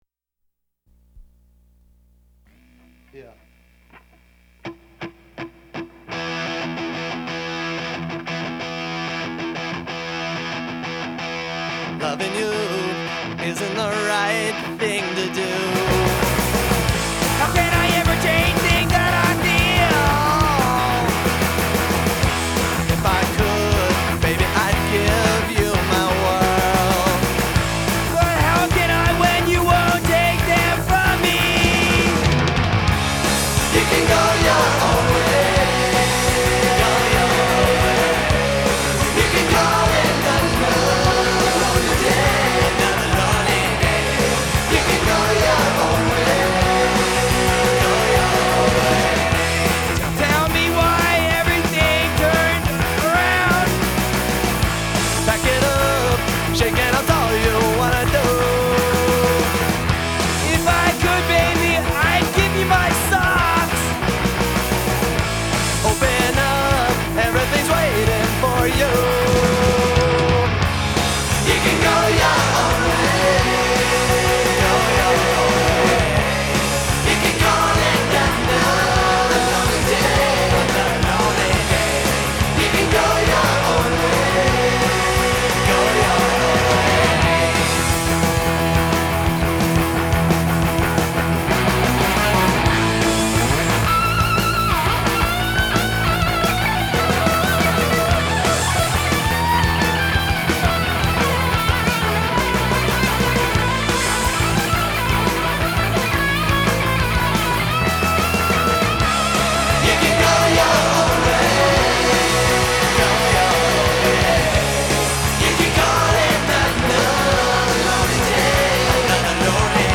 ble etter sigende spilt inn og ferdigstilt på under en uke.